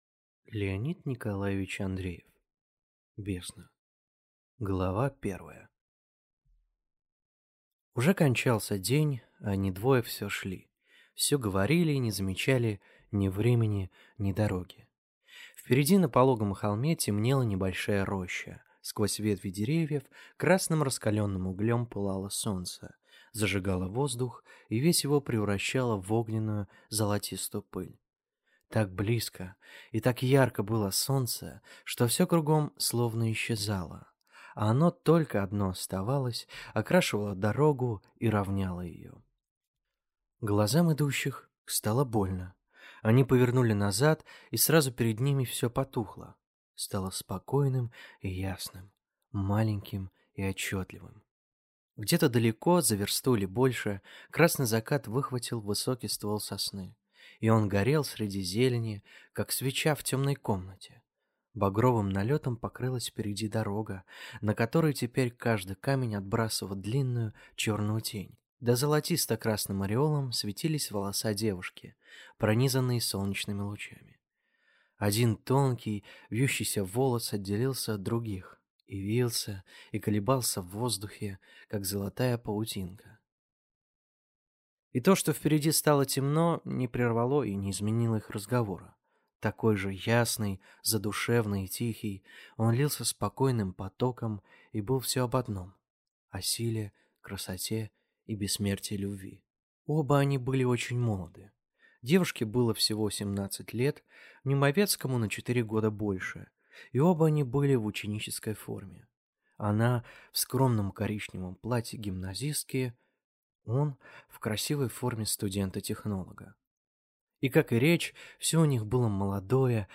Аудиокнига Бездна | Библиотека аудиокниг